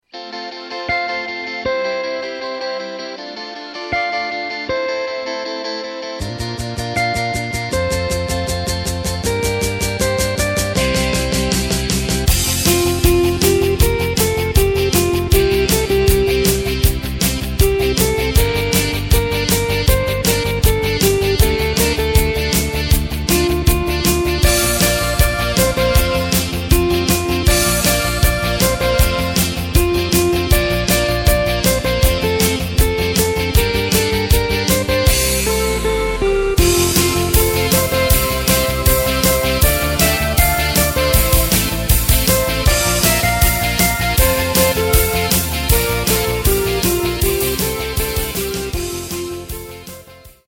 Takt:          4/4
Tempo:         158.00
Tonart:            F
Discofox aus dem Jahr 2015!